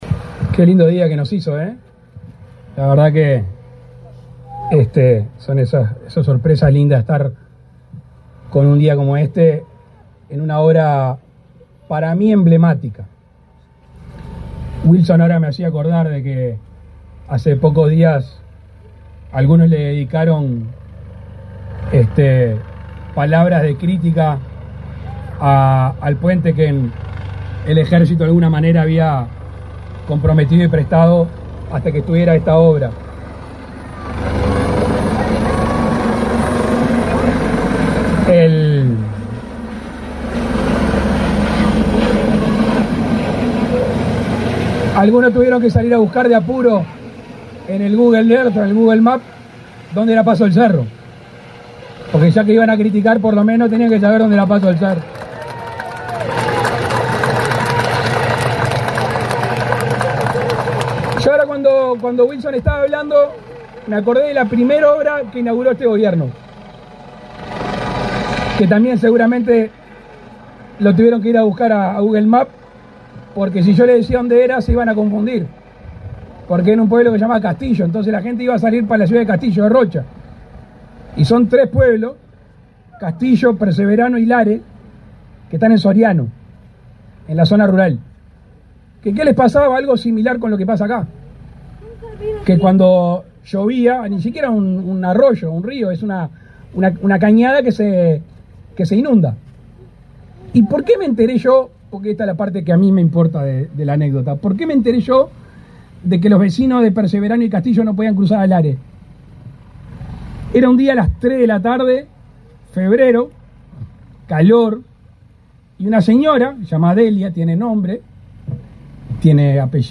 Palabras del presidente de la República, Luis Lacalle Pou
El presidente de la República, Luis Lacalle Pou, participó, este 2 de setiembre, en la inauguración del puente en Paso del Cerro que unirá la ciudad